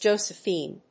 Jo・se・phine /dʒóʊzɪfìːndʒˈəʊ‐/
• / dʒóʊzɪfìːn(米国英語)
• / dʒˈəʊzɪfìːn(英国英語)